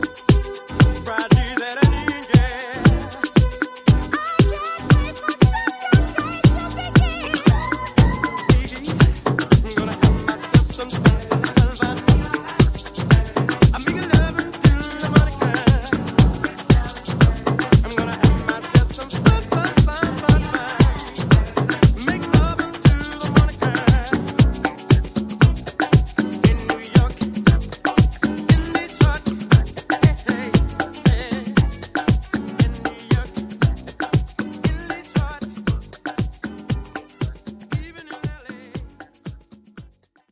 New Drum v2 Voc